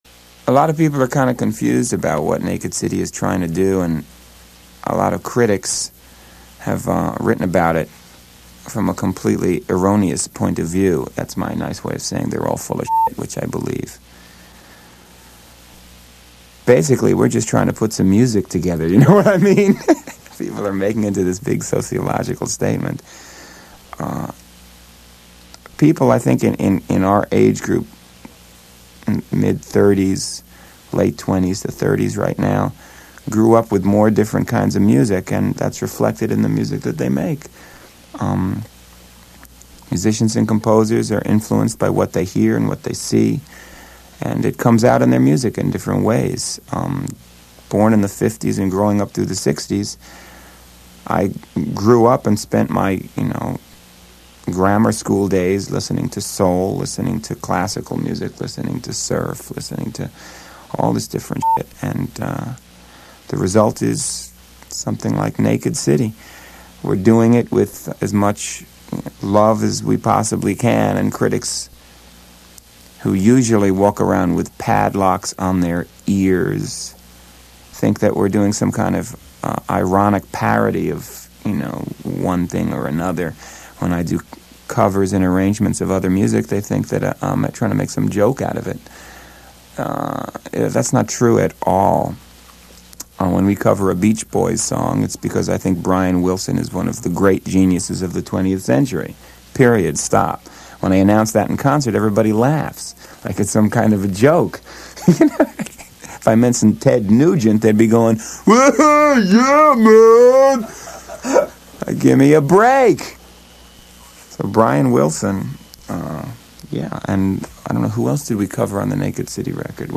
This was an hour long show of John Zorn playing songs that impacted his life and that he thought everyone should hear, including tracks from his band then, Naked City.
Here talks about his band Naked City: